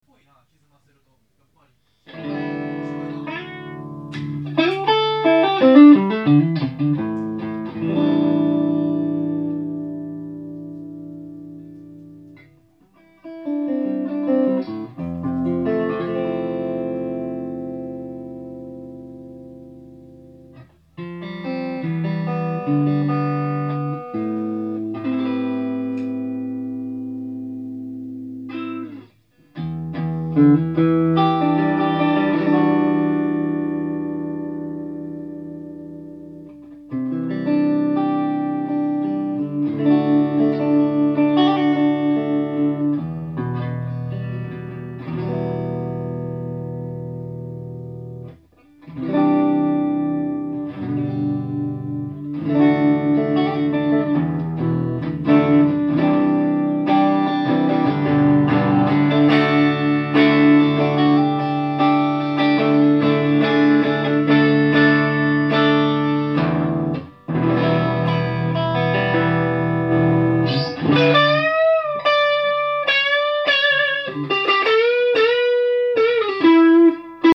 ただ、録音状態が悪く、メディアから拾うのに時間が掛
ProJr+Neo　普通。無難。